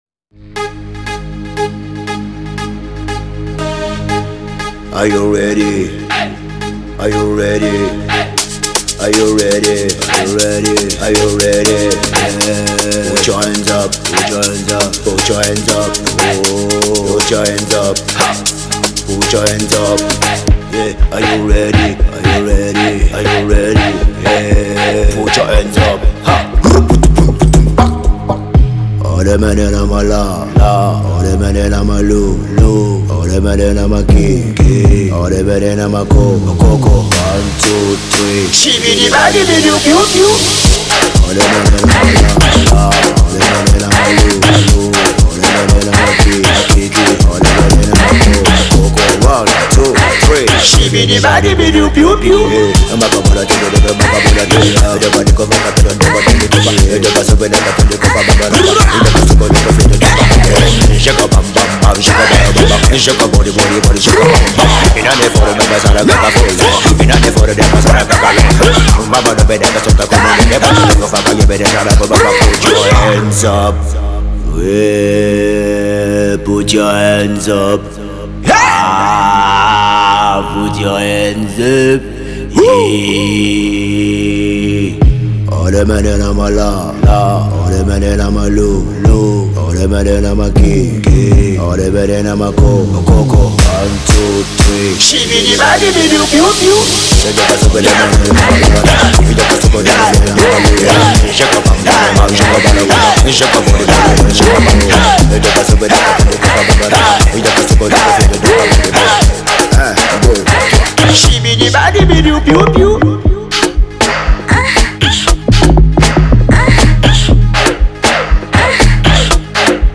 | Amapiano